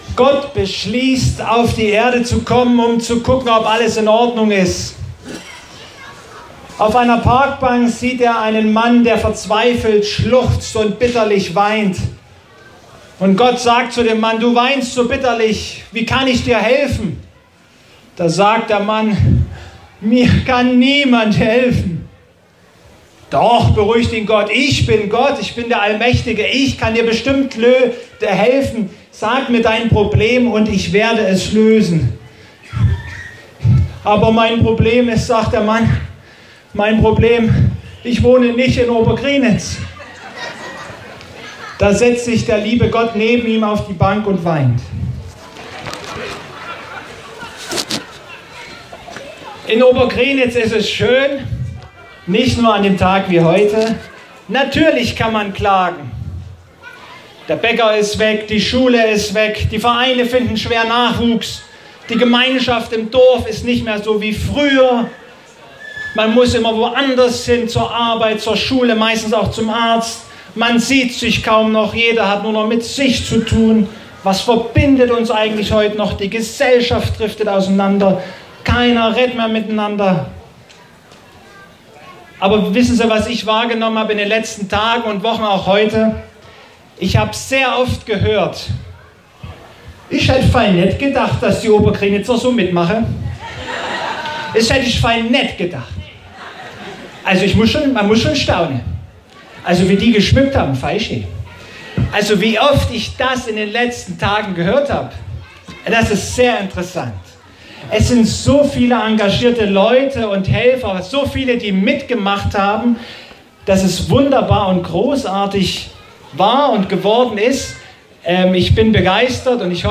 Hier die Predigt vom Gottesdienst dieses Festtages.
Lasst-die-Kirche-im-Dorf-Predigt-Mt-724-27.mp3